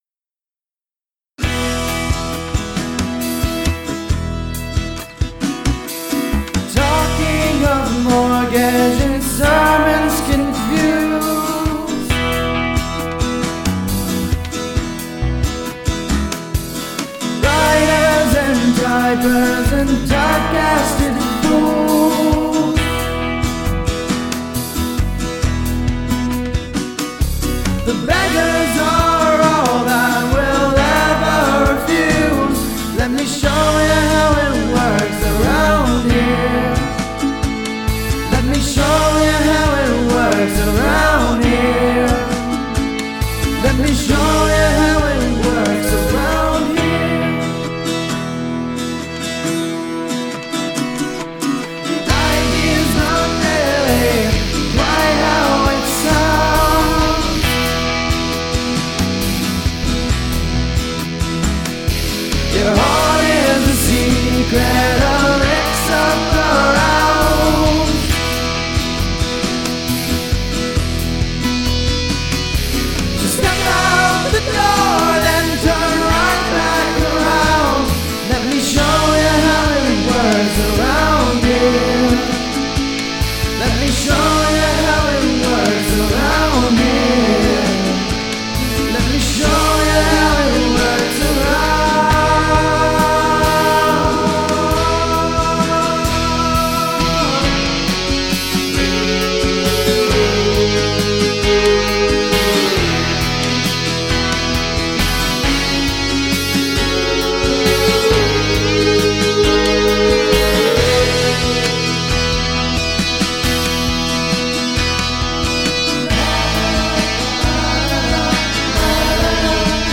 I had recorded an acoustic version, and they re-arranged an instrumental for it, and I recorded the vocals over their mix.
(If it helps, I'm running an AT2020 into a Zoom H4n onto Audacity lol)
Vocals a bit washed in delay/reverb for my taste but it works.
Yeah, that hi-hat needs some taming.
I also immediately sensed a lot of buildup in the upper mids and top end in general. It's rather bright and bordering harsh.
The fuzzed guitar that comes in during the 2nd verse interferes with the lead vocals as its panned right in the center too.
The slap-back predelay washes out his voice.
Harmony vocals were nice.
And yes the high hat is very hissy.
Snare and kick are a little soft.
The distorted guitars have a digital/amp-simmy sound to them.
Bass was OK, maybe a little soft.